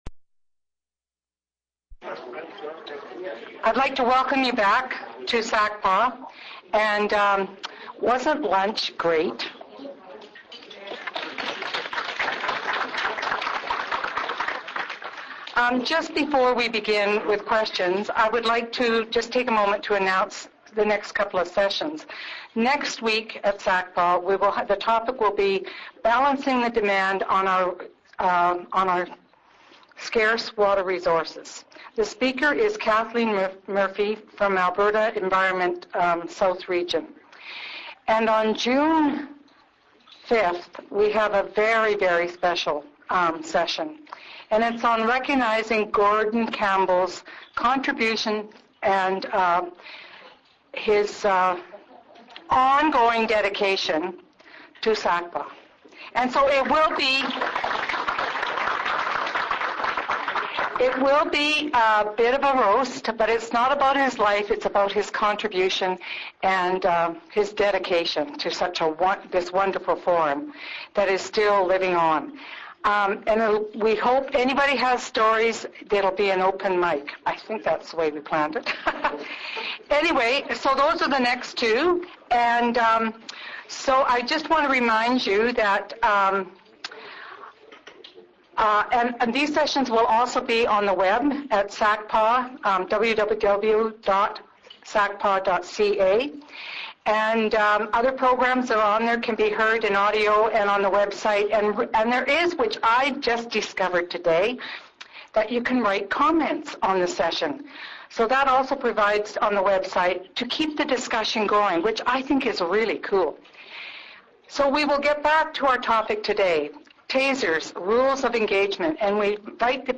Location: Country Kitchen Catering (Lower level of The Keg)